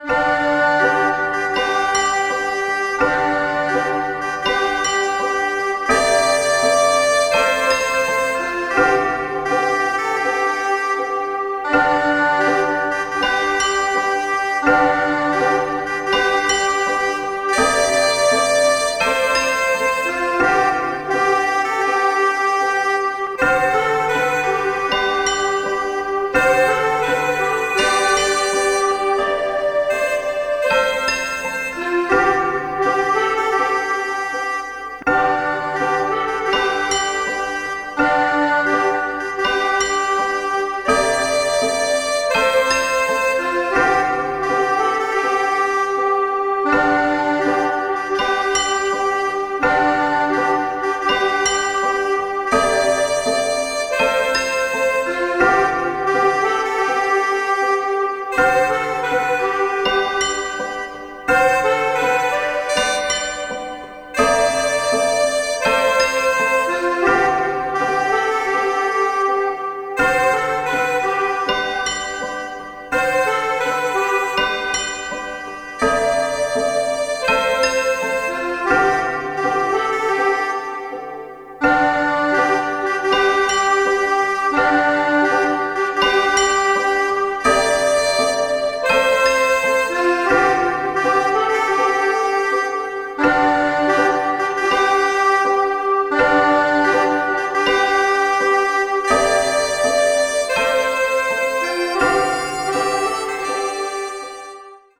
Style: Instrumental - Klassik Piano
Musik für besinnliche Stunden - Frohe Tage - Bleibt gesund!